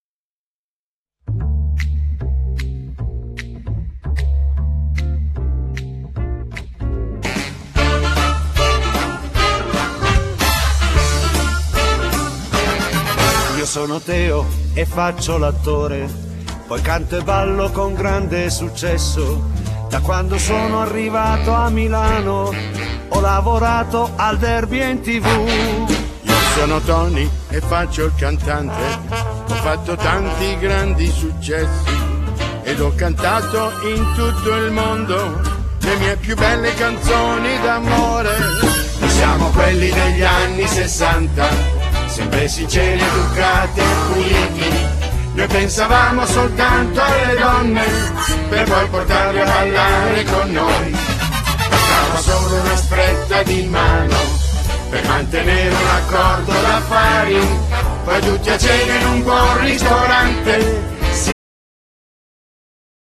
Genere : Pop funky